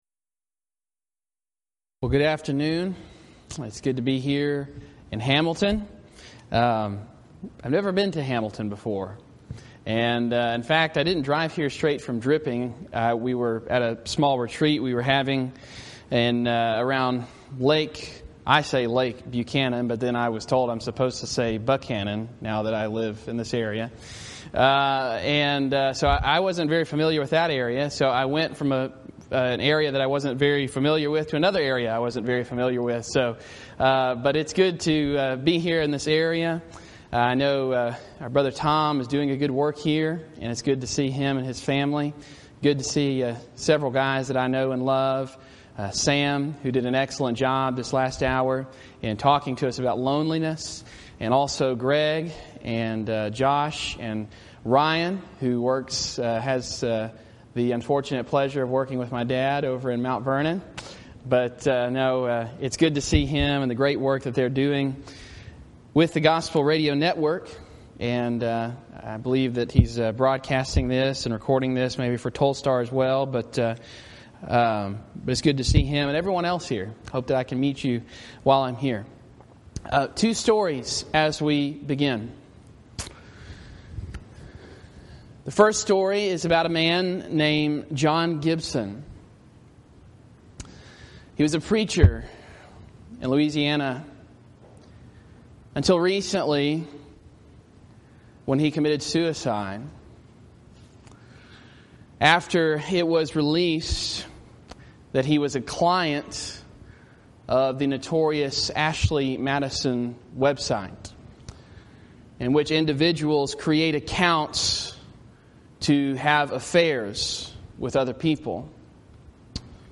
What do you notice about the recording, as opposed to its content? Event: 6th Annual Back to the Bible Lectures Theme/Title: Emotional Issues Facing the Church